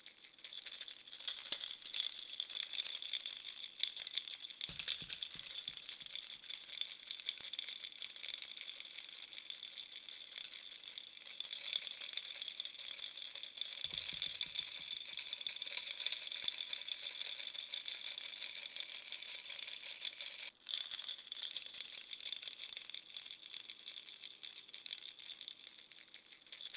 В подборке собраны варианты с разным тембром и интенсивностью звучания: от тихого шуршания до громкого треска.
Отвлекающие звуки погремушки для новорожденных